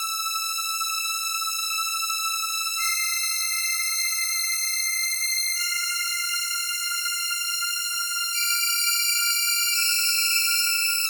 Sorar Strings 02.wav